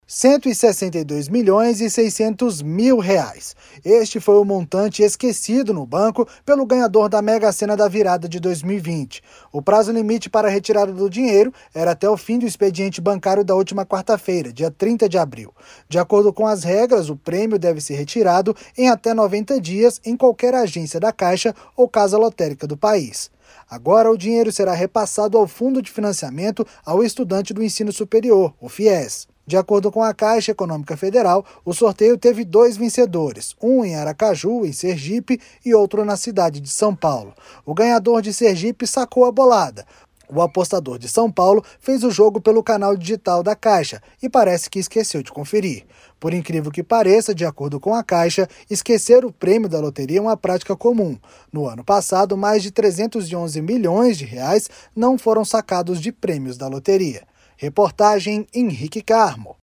BOLETIM_MEGA_SENA.mp3